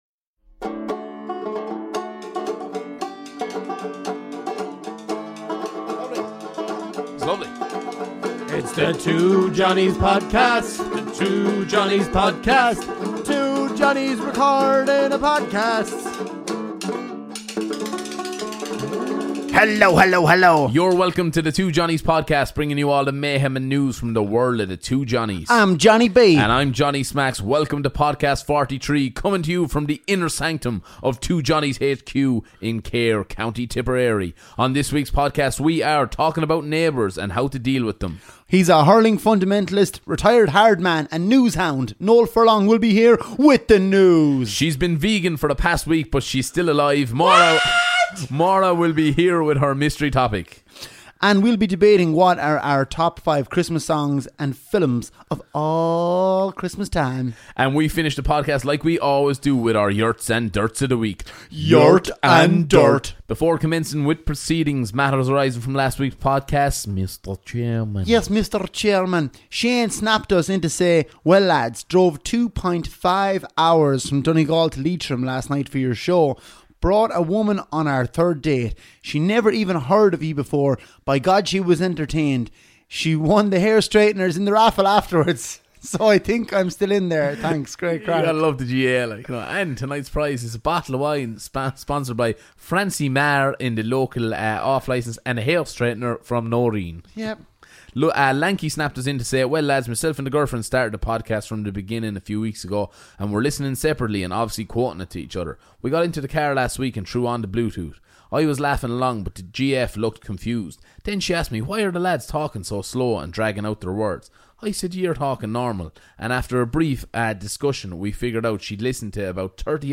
Ireland's favourite comedy duo tackle the big issuesThis week: Neighbours, the good, bad & crazy. The 2 Johnnies guide how to handle them.